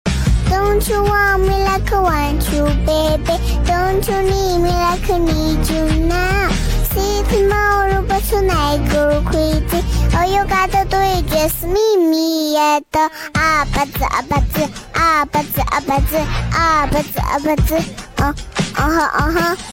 Auto Tuned Meow: Kitten Drops a sound effects free download